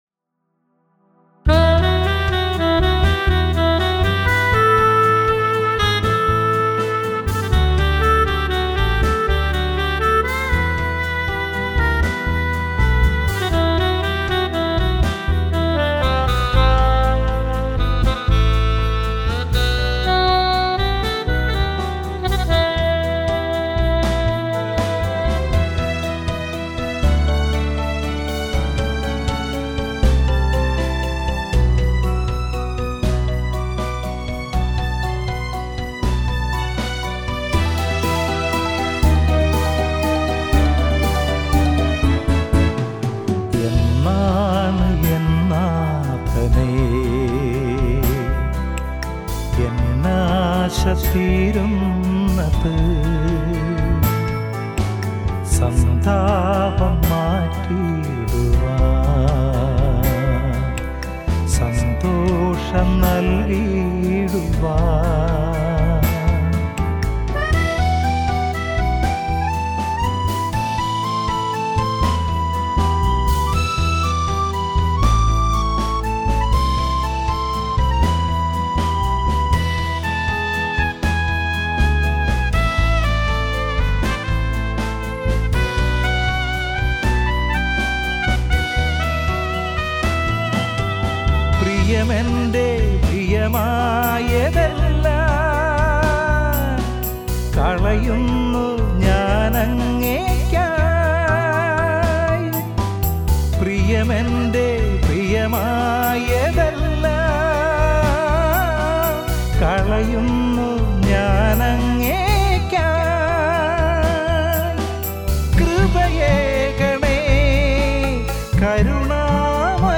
I did this song using Cubase 5, using VSTs like HalionOne and EZdrummer. The vocals were recorded using Neumann U87.